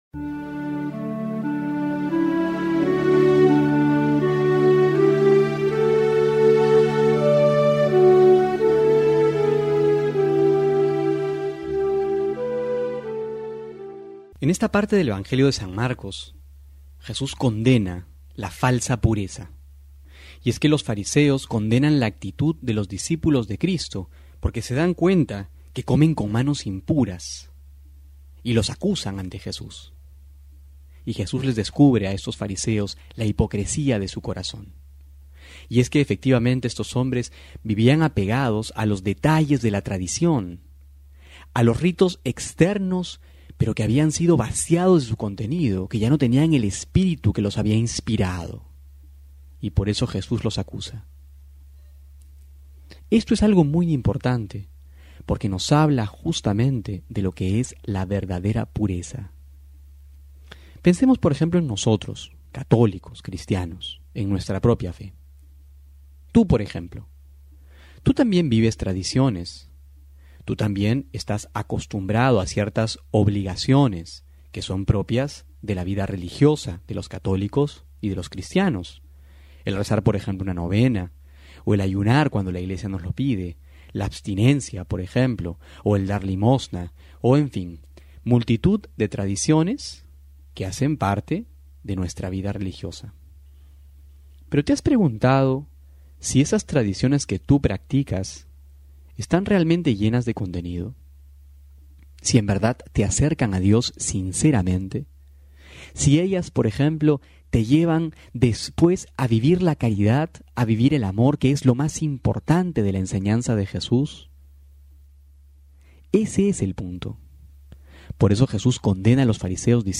Homilía para hoy: Marcos 7,1-13
febrero07-12homilia.mp3